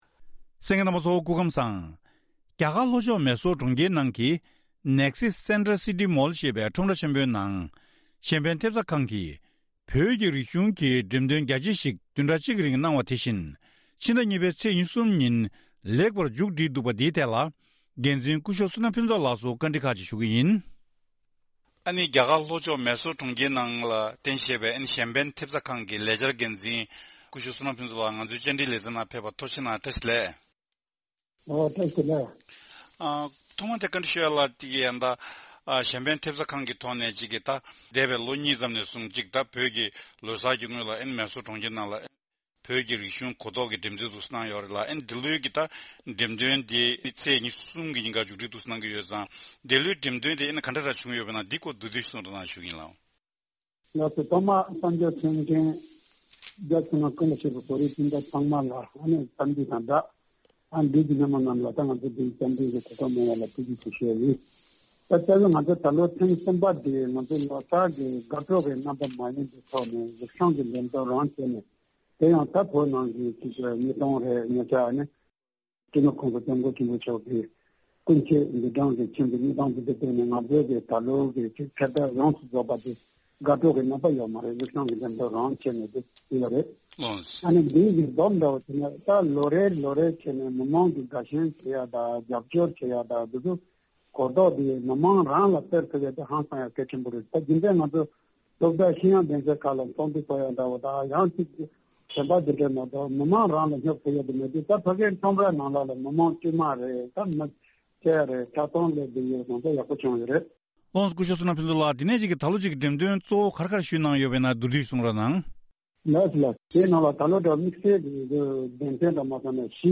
དེ་རིང་གནས་འདྲིའི་ལེ་ཚན་ནང་།